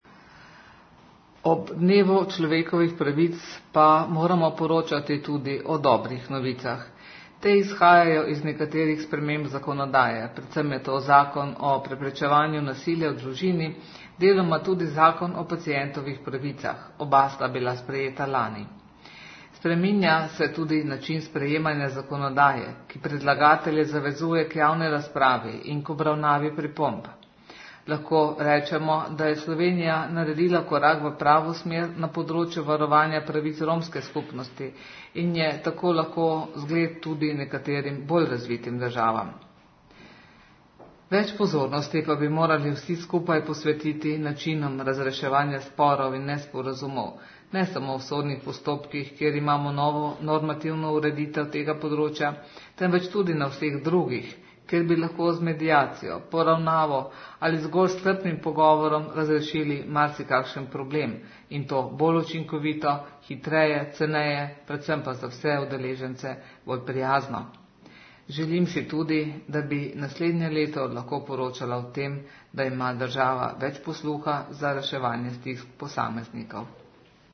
ZVOČNI POSNETEK POSLANICE
Dr. Zdenka Čebašek-Travnik, dr. med.
Varuhinja človekovih pravic
Govori